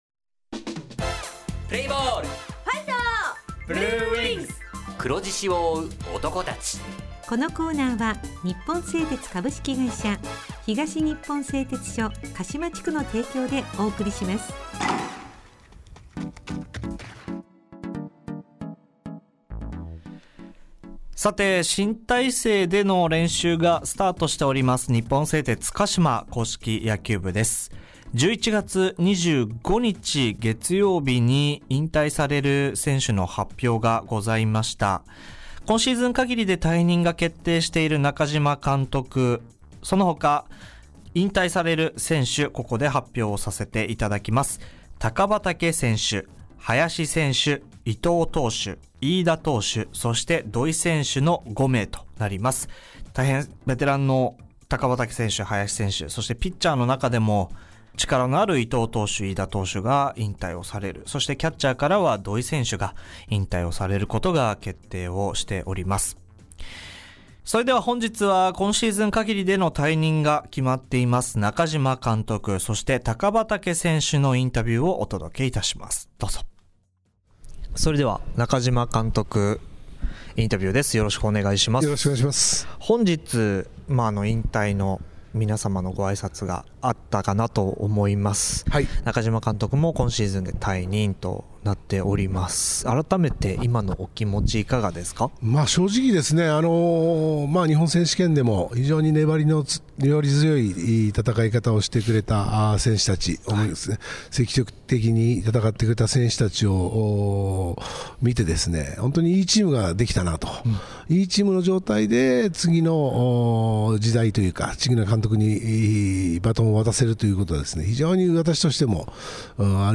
地元ＦＭ放送局「エフエムかしま」にて鹿島硬式野球部の番組放送しています。（７６．７ｍｈｚ）